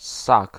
Ääntäminen
US GenAm: IPA : /ˈmæməl/